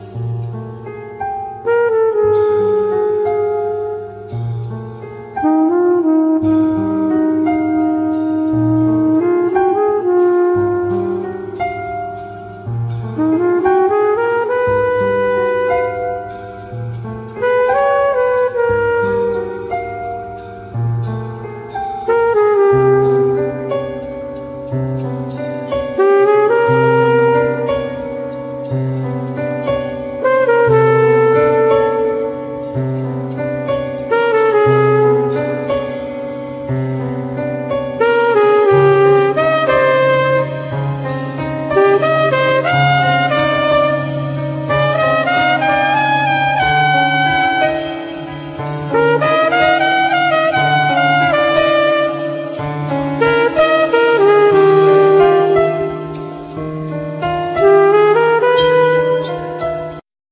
Trumpet
Piano
Percussion